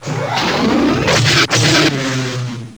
corelocklaser.wav